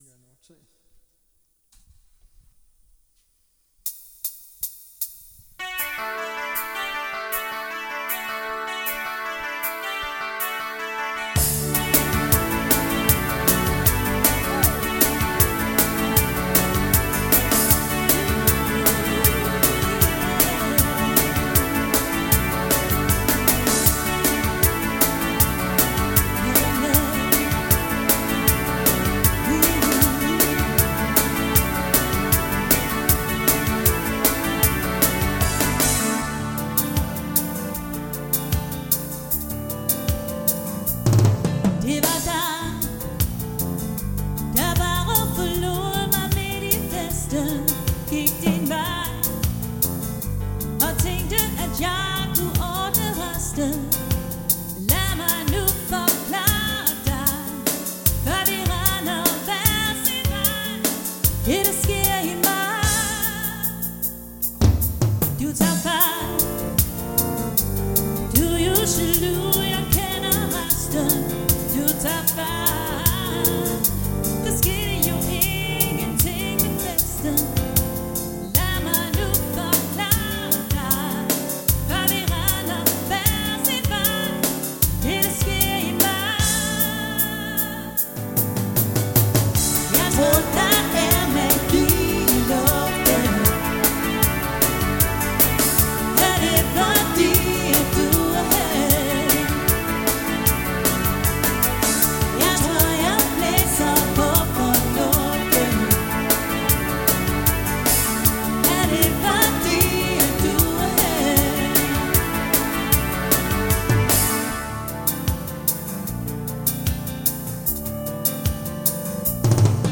• Coverband
• Duo eller trio